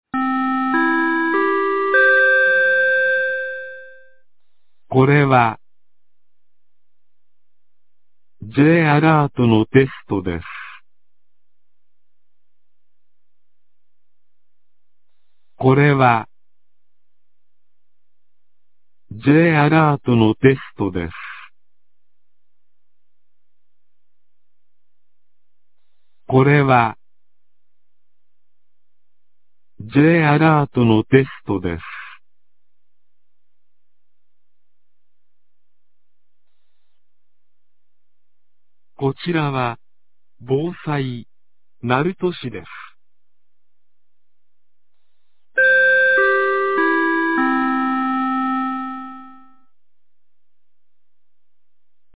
2026年02月06日 11時01分に、鳴門市より全地区へ放送がありました。